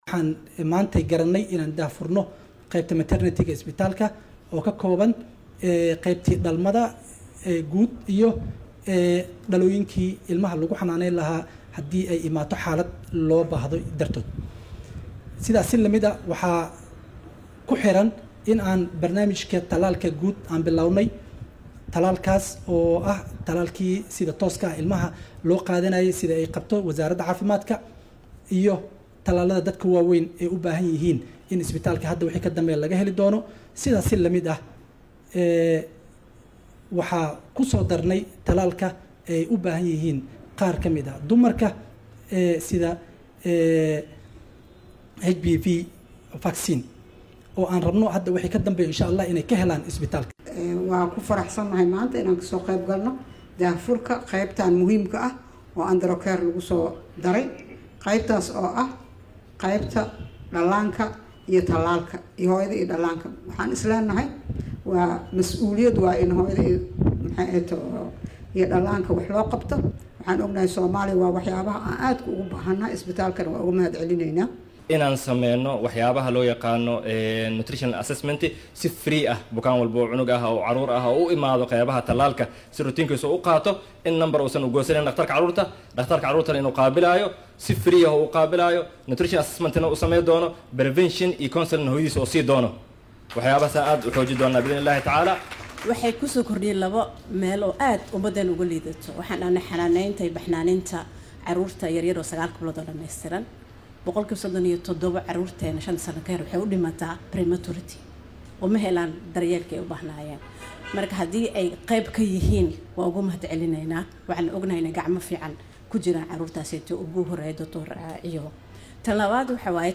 Wasiiru dowlaha Wasaaradda Caafimaadka ee Xukuumadda Federaalka Soomaaliya Dr. Maryan Maxamad Xuseen ayaa xariga ka jartay Barnaamijka Qaran ee Talaalka Daryeelka Hooyada iyo Dhallaanka, oo lagu qabtay Muqdisho. Dr Maryan iyo dhakhaatiirtii kale ee ka soo qayb gashay xafladda xarig jarka ayaa waxaa hadaladooda ka mid ahaa.